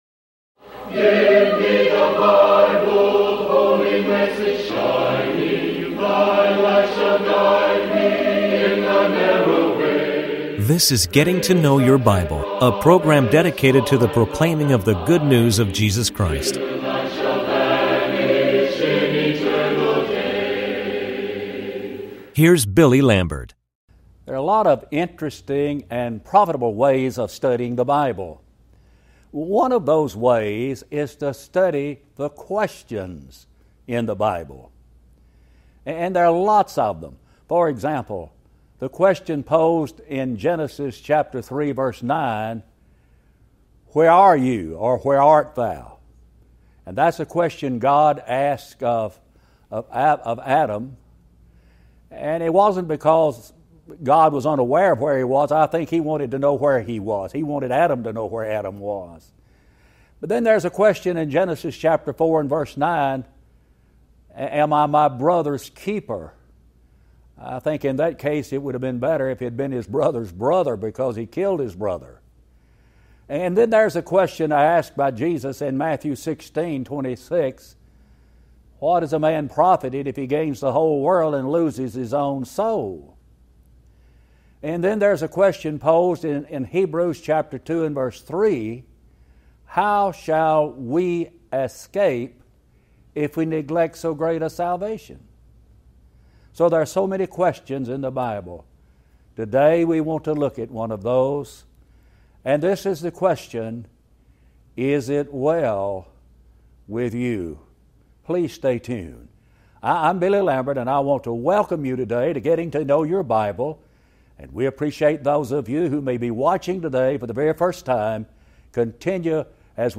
Talk Show Episode, Audio Podcast, Getting To Know Your Bible and Ep1346, Is It Well With You on , show guests , about Is It Well With You, categorized as History,Love & Relationships,Philosophy,Psychology,Religion,Christianity,Inspirational,Motivational,Society and Culture